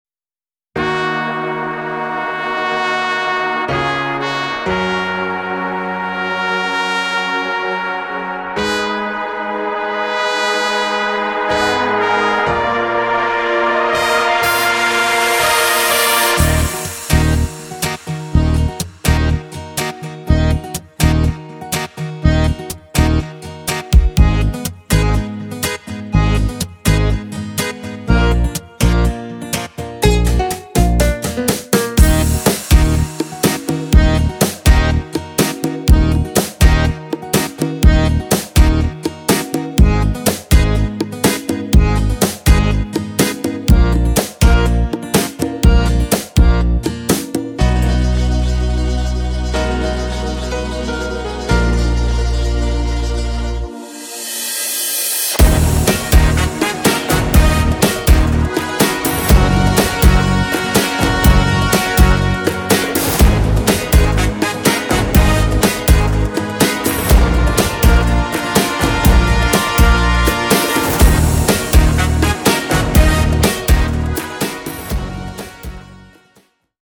Duet
Pop